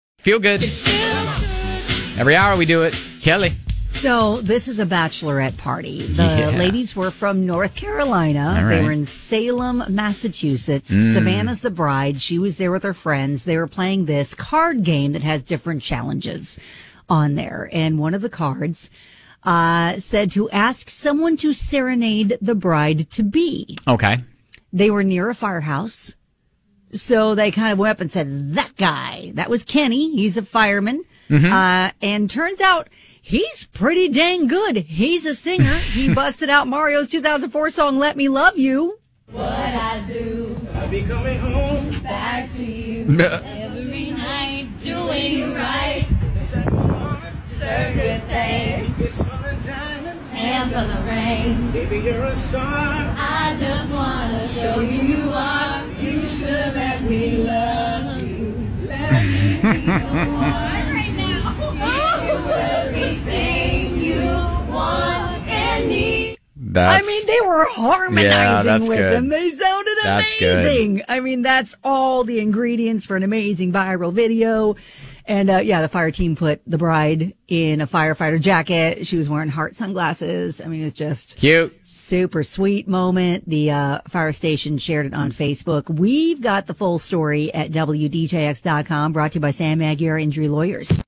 And the ladies brought harmonies for backup!